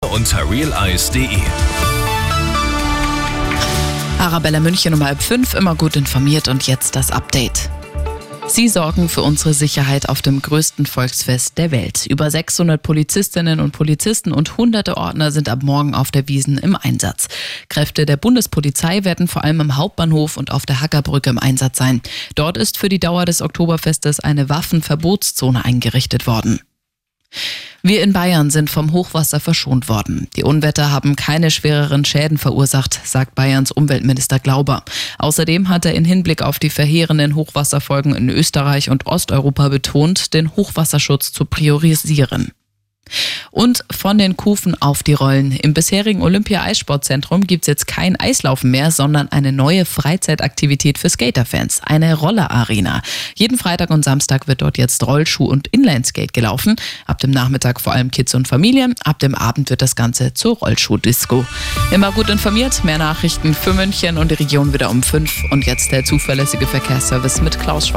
Die Radio Arabella Nachrichten von 19 Uhr - 20.09.2024